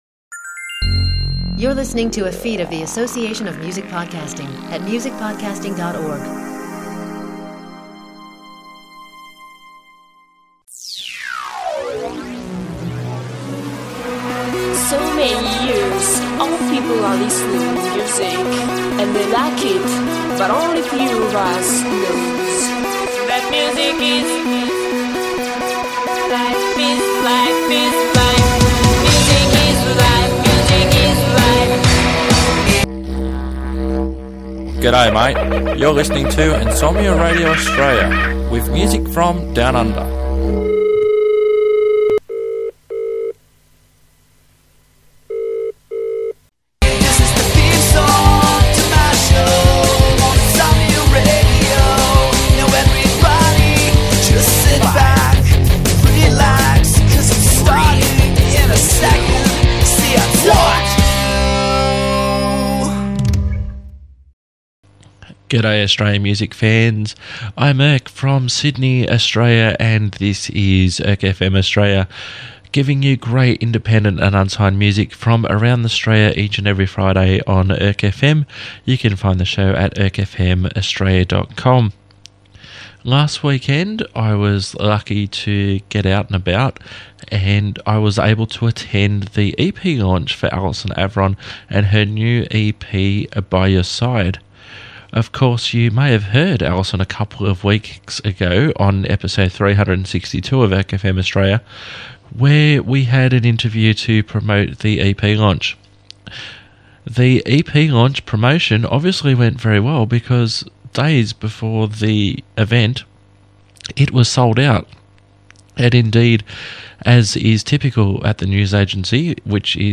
There will be a couple of live tracks to end the episode.